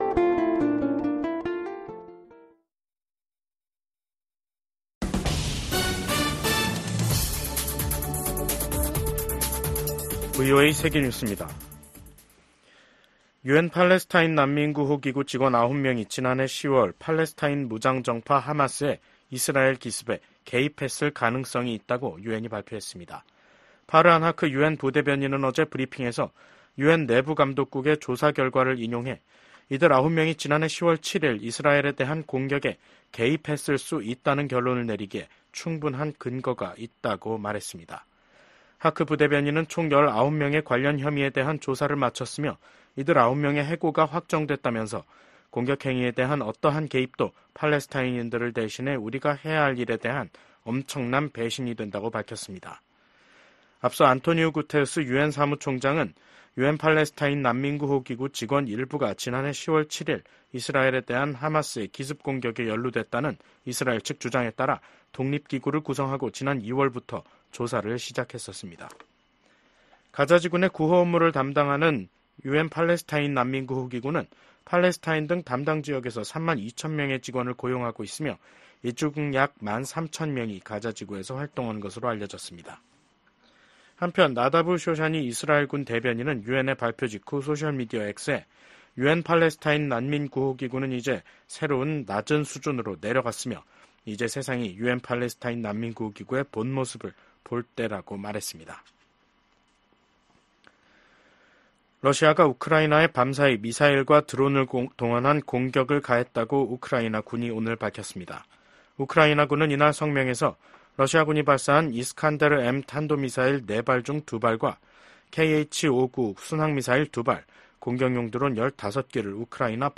VOA 한국어 간판 뉴스 프로그램 '뉴스 투데이', 2024년 8월 6일 2부 방송입니다. 북한이 신형 전술 탄도미사일 발사대를 공개한 것과 관련해 미국의 전문가들은 북한의 점증하는 위협에 맞서 탄도미사일 방어 역량을 강화해야 한다고 지적했습니다. 압록강 유역 수해 복구에 전 사회적 인력 동원에 나선 북한이 외부 지원 제의는 일절 거부하고 있습니다.